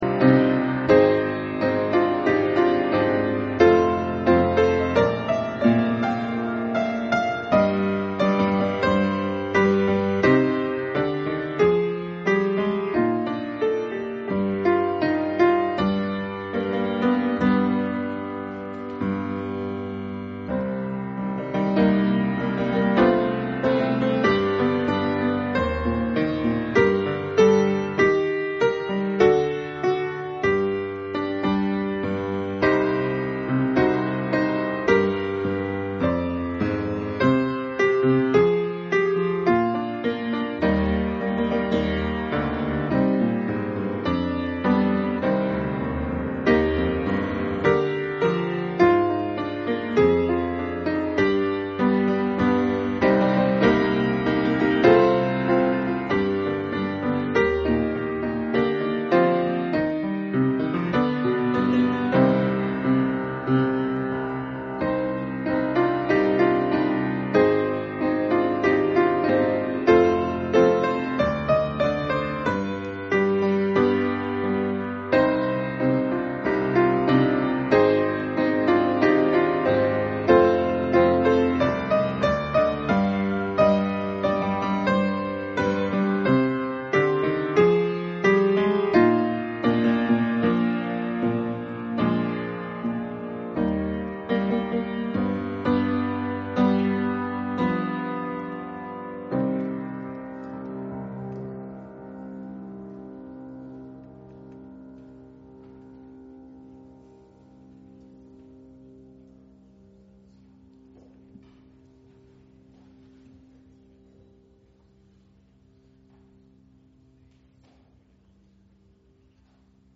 Public Reading of Holy Scripture
Service Type: Sunday Afternoon